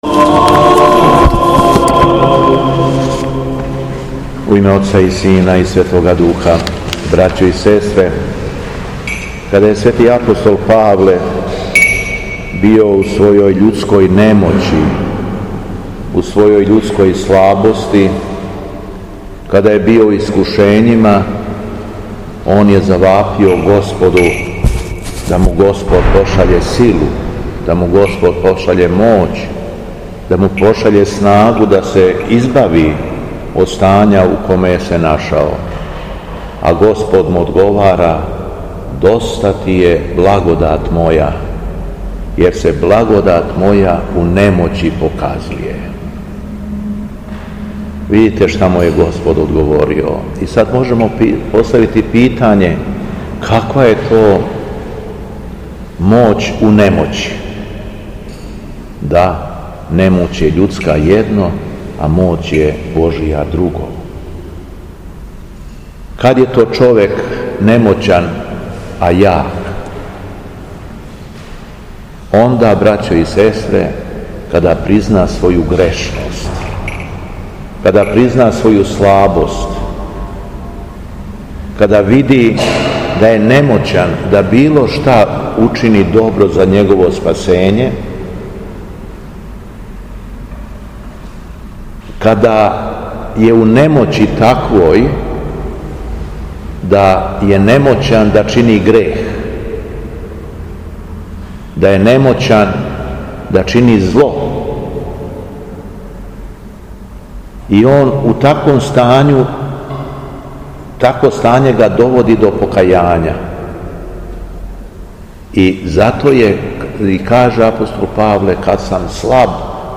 СВЕТА АРХИЈЕРЕЈСКА ЛИТУРГИЈА У ХРАМУ СВЕТОГА САВЕ У КРАГУЈЕВАЧКОМ НАСЕЉУ АЕРОДРОМ - Епархија Шумадијска
Беседа Његовог Преосвештенства Епископа шумадијског г. Јована